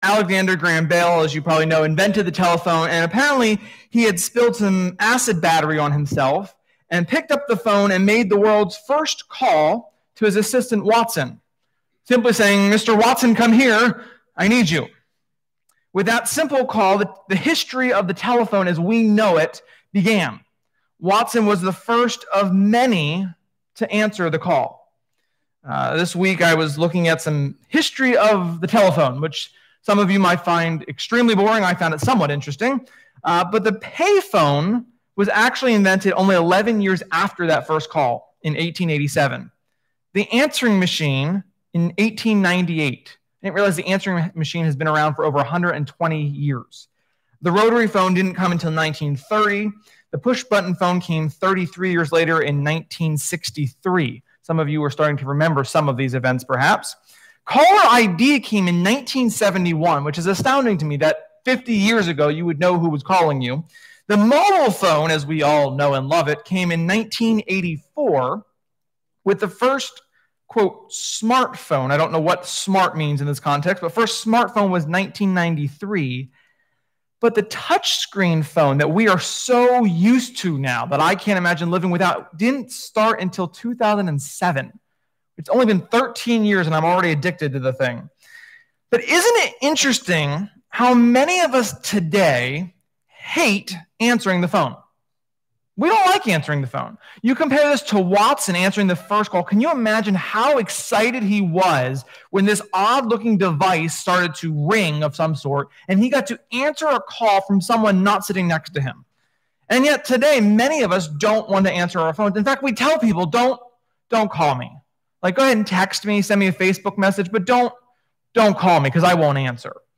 Sermon-8.23.20.mp3